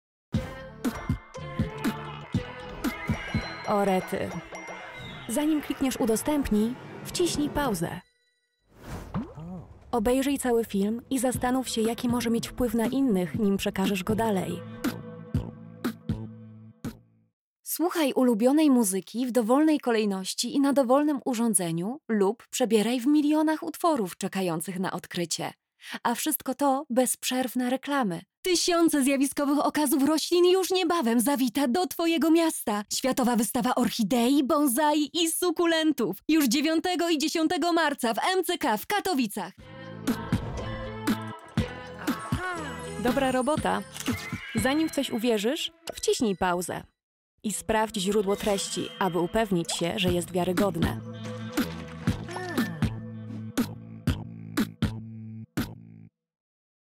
Commerciale, Naturelle, Amicale, Chaude, Douce
Commercial
Thanks to her acting experience, she has a wide vocal range and excellent diction.